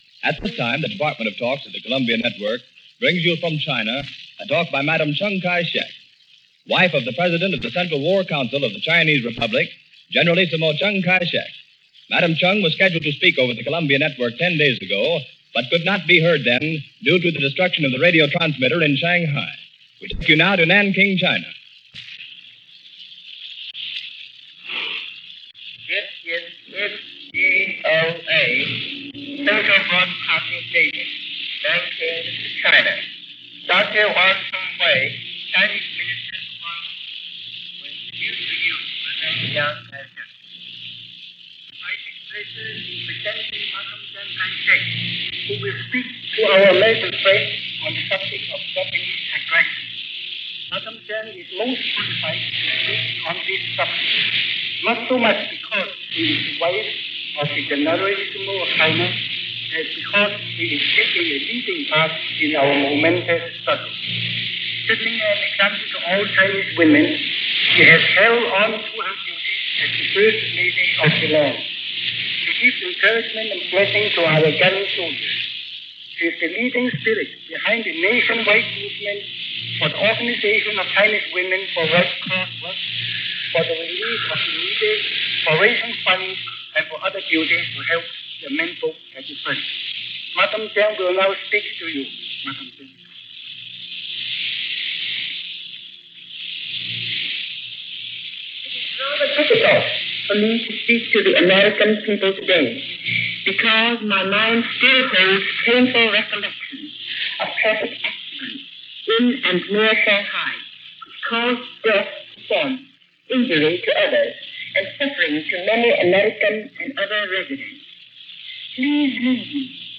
Madame Chiang-Kai Shek – Talk From Shanghai